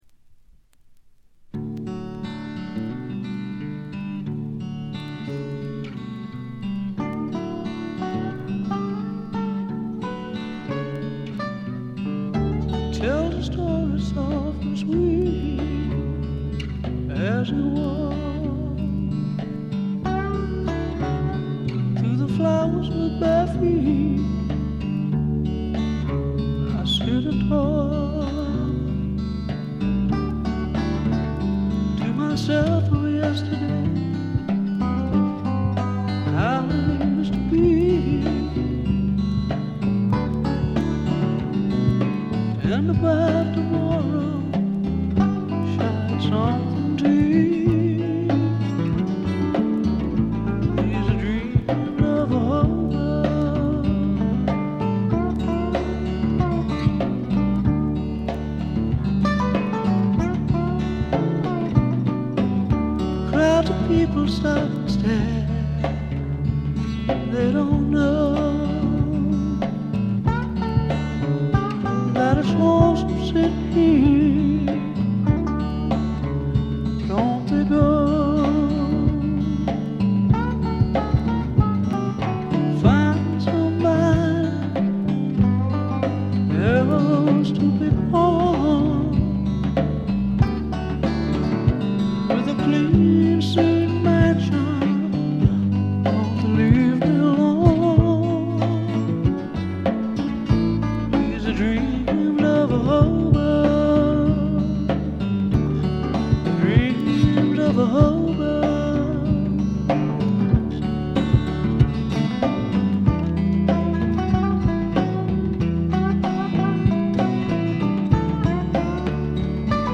部分試聴ですがチリプチ少々程度。
スワンプ・ロックの基本定番！！！
試聴曲は現品からの取り込み音源です。
January 1971 Olympic Sound Studios, Barnes, London